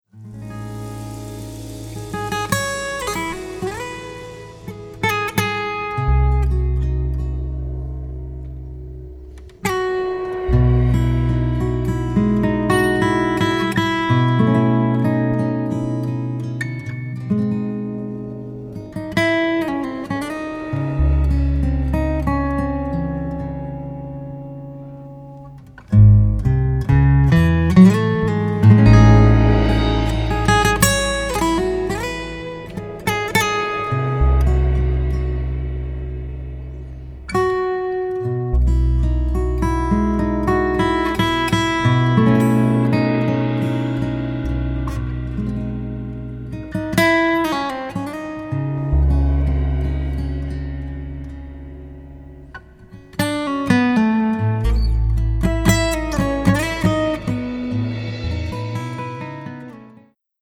Studiorecording
Guitar
Drums
Bass